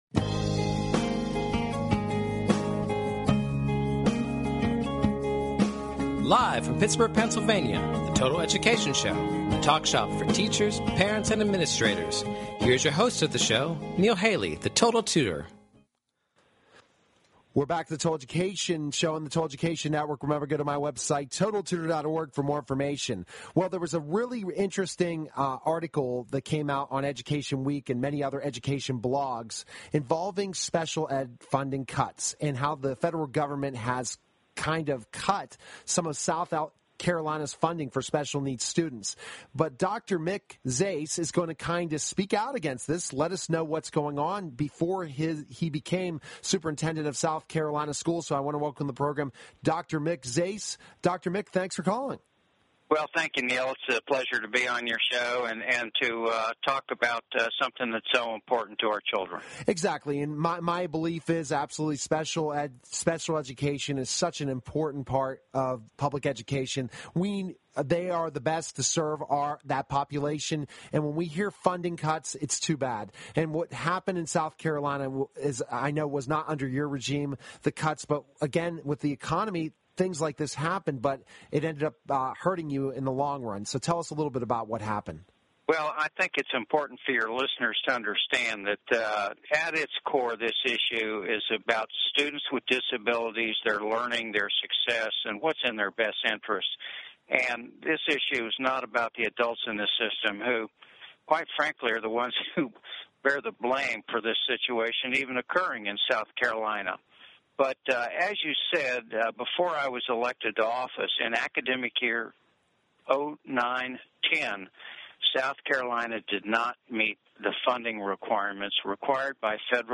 Total Education Celebrity Show {also known as "The Total Education Hour" is an educational talk show that focuses on the listeners' needs. Catch weekly discussions focusing on current education news at a local and national scale.